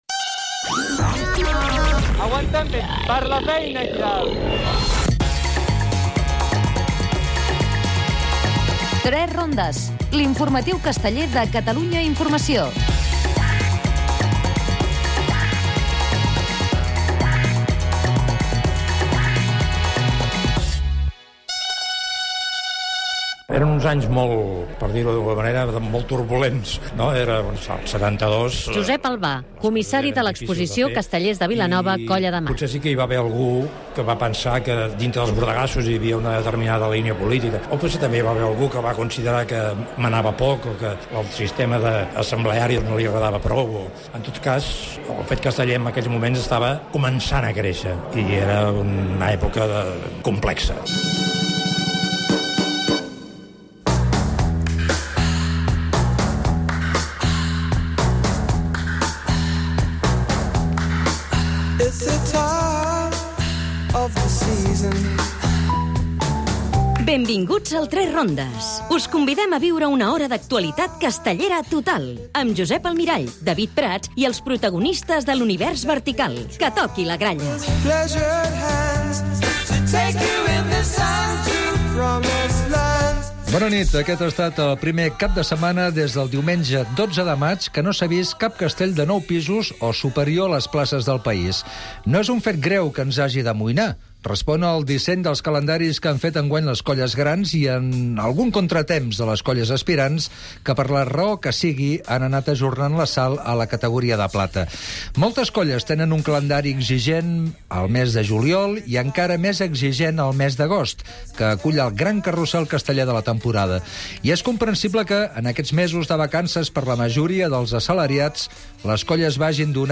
Reportatge de la desapareguda Colla de Mar de Vilanova.